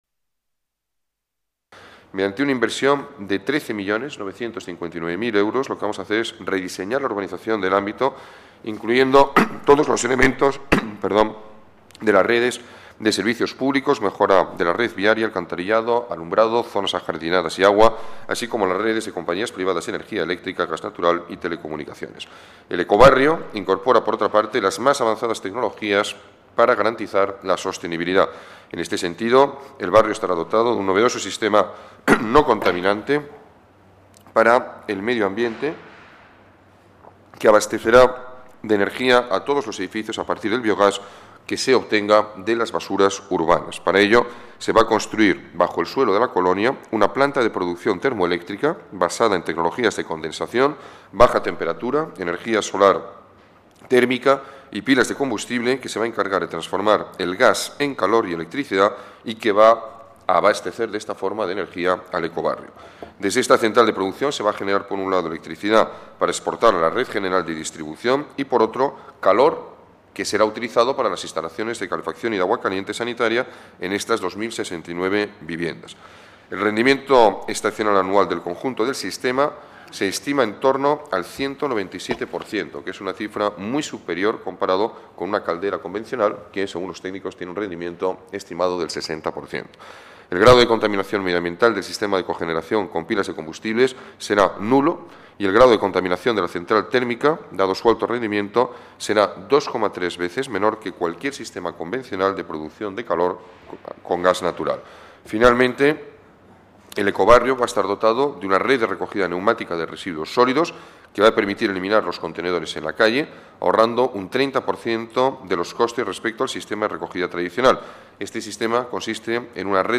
Nueva ventana:Declaraciones del alcalde en las que informa de la transformación de dos colonias municipales en el primer ecobarrio de Madrid